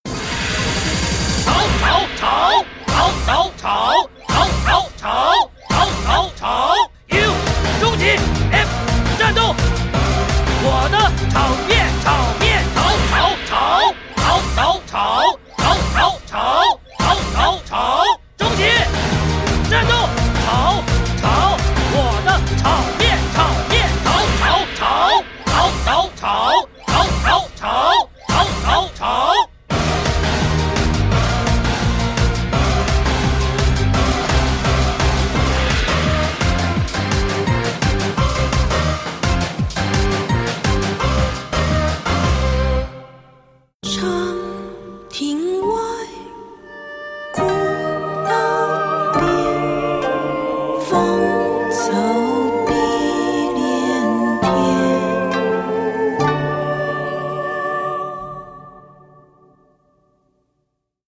中国民謡